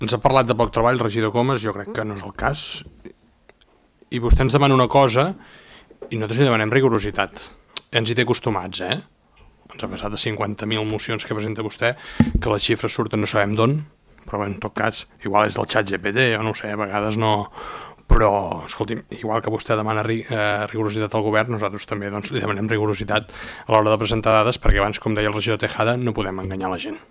El ple municipal de Calella va viure un debat intens sobre la situació de l’habitatge arran d’una moció presentada per la CUP per completar el recompte d’habitatges buits i activar mesures municipals d’intervenció.
L’alcalde Marc Buch també va intervenir per reclamar rigor en l’ús de les dades.